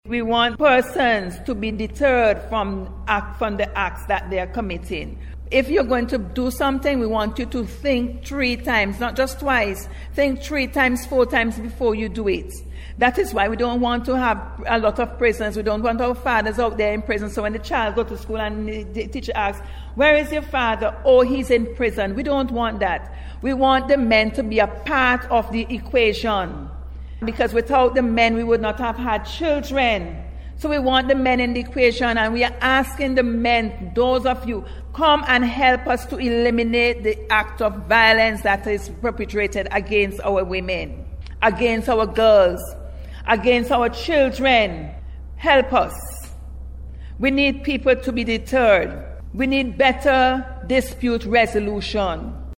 News Conference